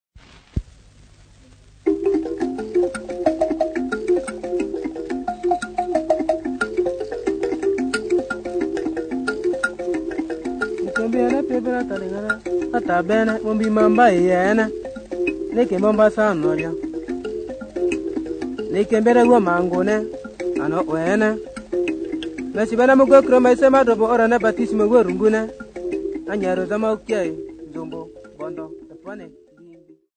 Folk Music
Field recordings
Africa Democratic Republic of Congo city not specified f-sa
sound recording-musical
Indigenous music